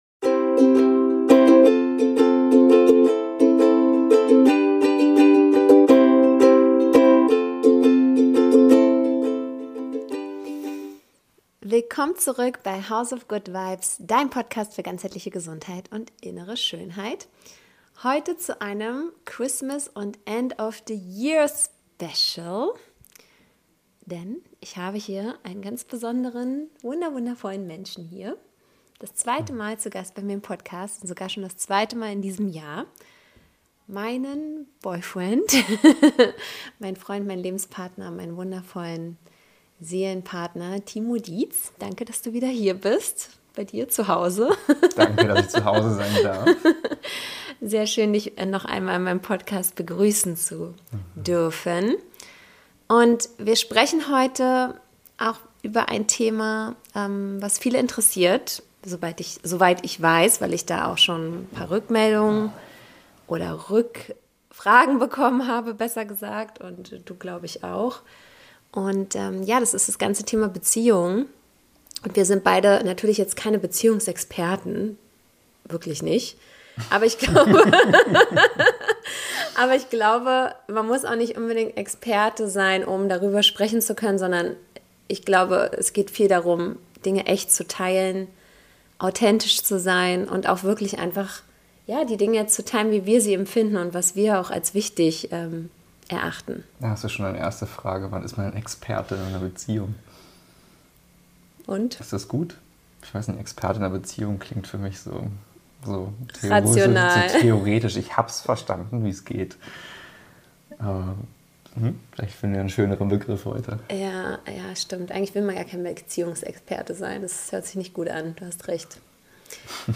Dieses Gespräch ist ein persönlicher und offener Austausch über Beziehungserfahrungen, Gedanken in der Partnerschaft und darüber, warum echte Unterstützung so viel mehr ist als Worte oder Gesten.